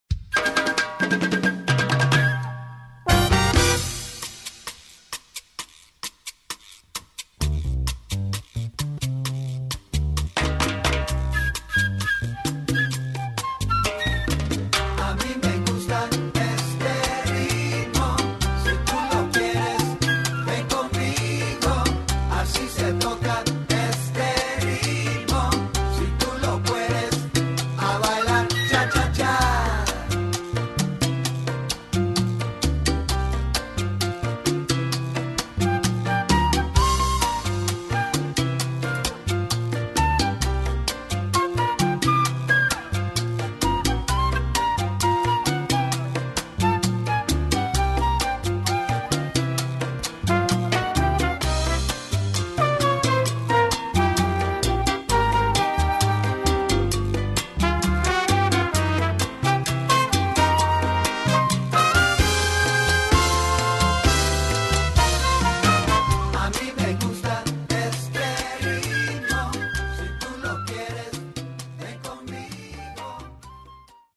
Category: little big band
Style: cha cha
Solos: open